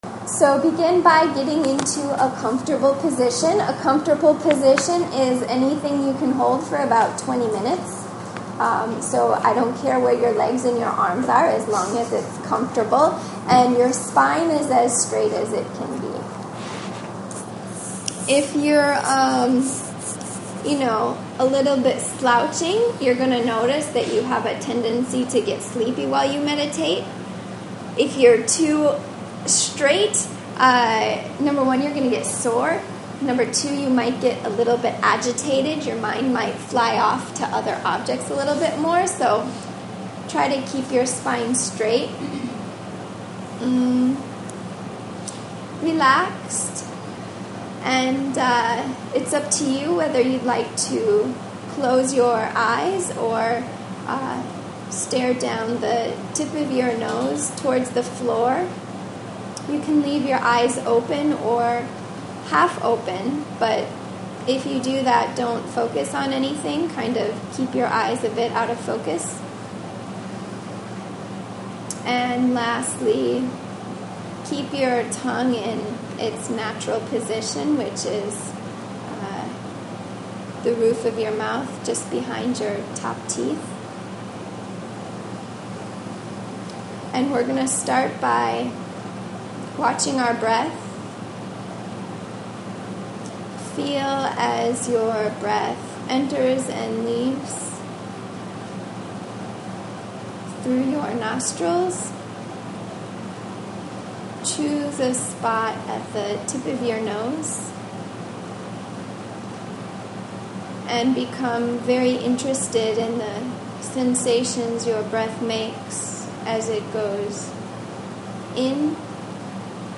Meditation1.mp3